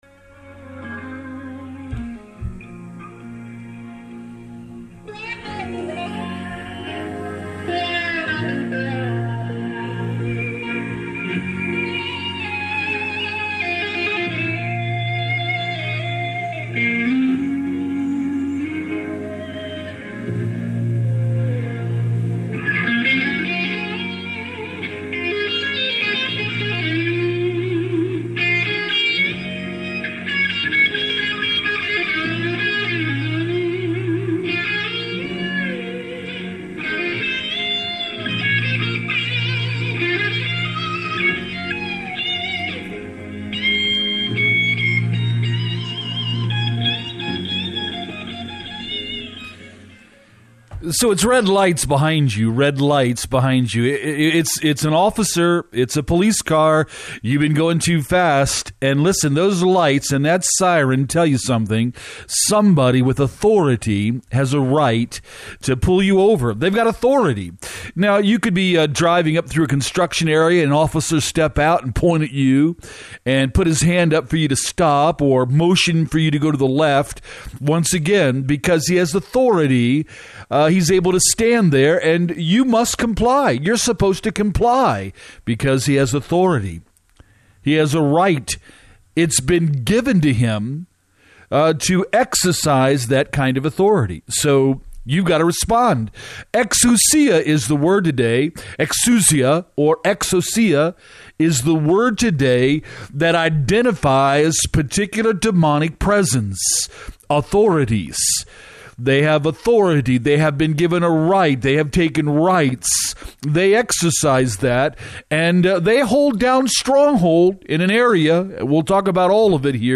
TAKING OF THE PRESENTATION (MASKS) AND LOOKING AT THESE BEINGS AS THEY REALLY ARE TUESDAY APRIL 12TH SHATTER LIVE RADIO BROADCAST 6PM EXOUSIAS, what they are and what they do.